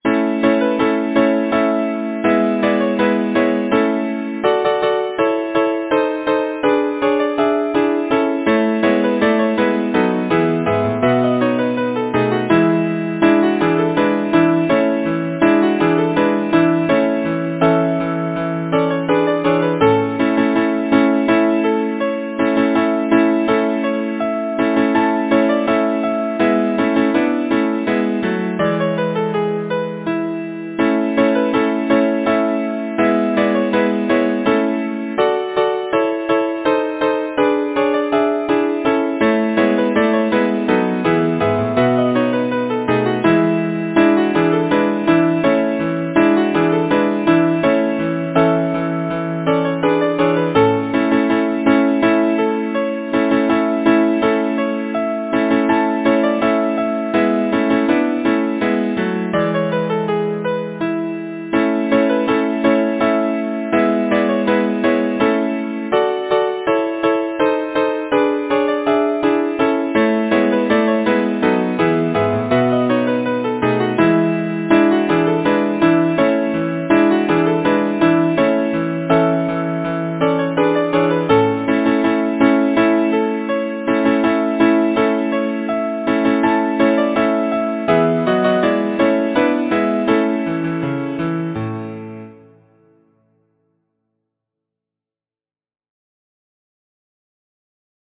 Title: Philomela Composer: Ann Mounsey Lyricist: William Bartholomew Number of voices: 4vv Voicing: SATB Genre: Secular, Partsong
Language: English Instruments: A cappella